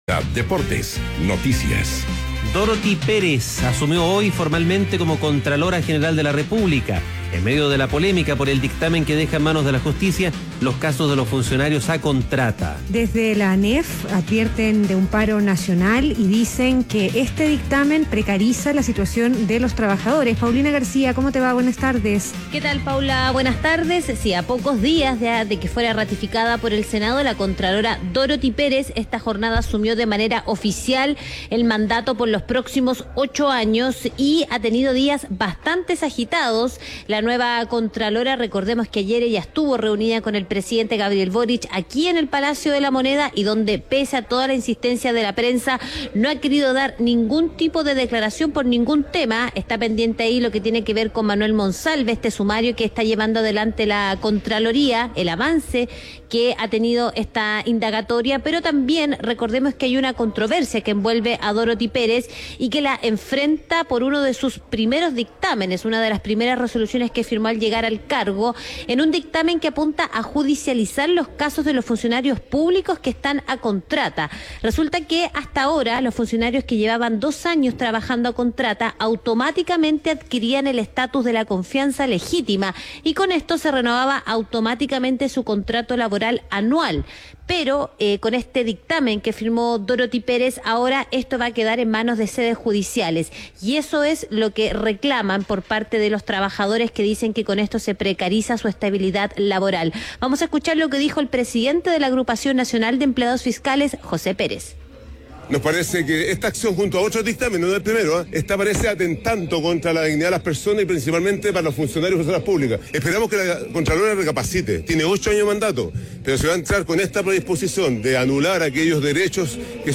En una ceremonia realizada en dependencias del órgano contralor, Dorothy Pérez entregó su primer mensaje para dar inicio a su mandato de 8 años.
Esto se llevó a cabo durante una ceremonia en las dependencias del órgano contralor, donde asistió el Presidente Gabriel Boric, junto a autoridades de Gobierno.